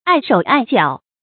礙手礙腳 注音： ㄞˋ ㄕㄡˇ ㄞˋ ㄐㄧㄠˇ 讀音讀法： 意思解釋： 礙；妨礙；阻礙。